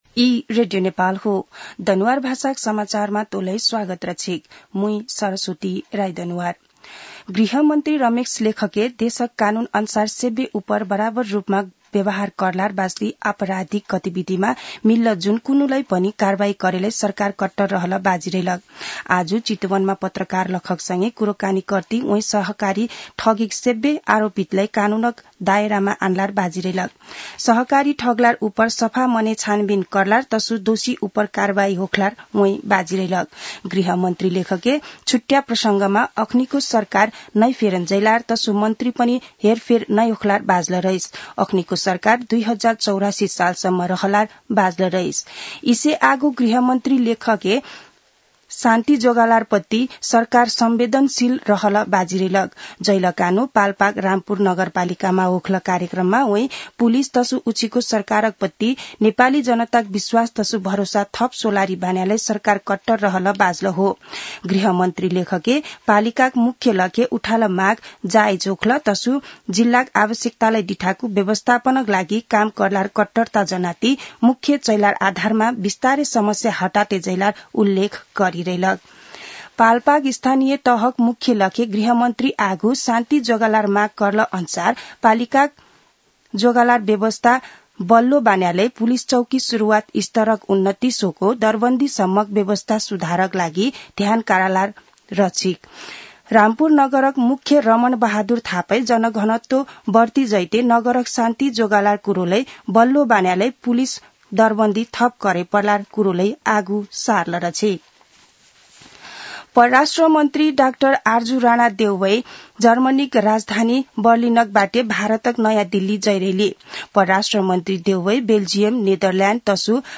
दनुवार भाषामा समाचार : ५ पुष , २०८१